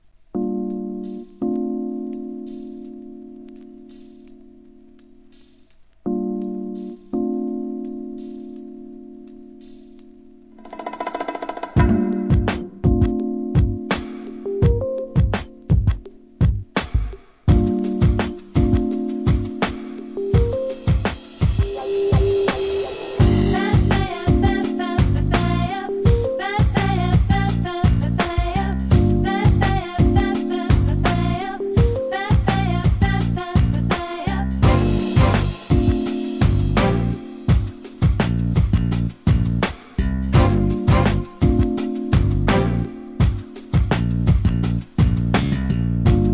HIP HOP/R&B
ブラジリアン・ブレイクビーツ!!